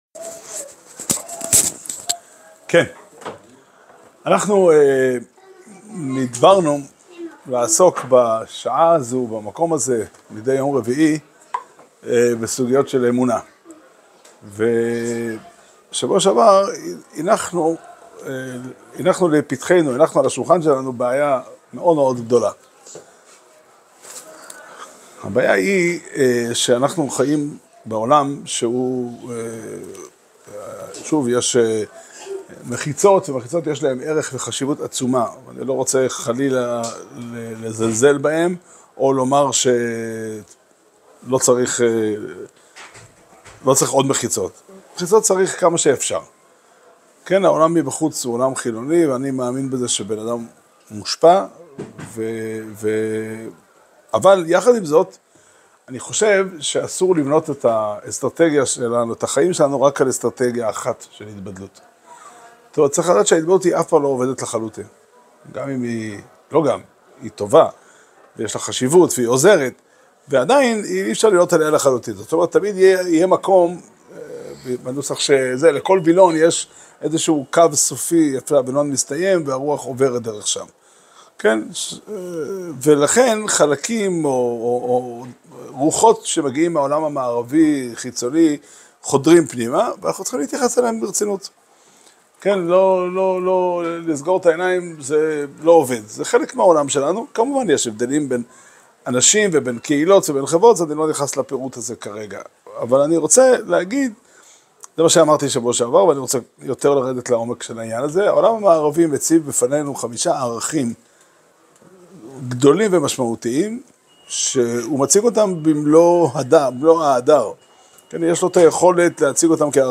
שיעור שנמסר בבית המדרש פתחי עולם בתאריך ג' כסלו תשפ"ה